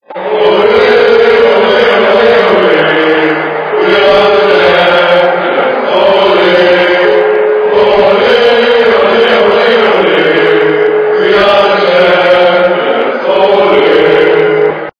» Звуки » Люди фразы » Фудбольная кричалка - Оле Оле Оле
При прослушивании Фудбольная кричалка - Оле Оле Оле качество понижено и присутствуют гудки.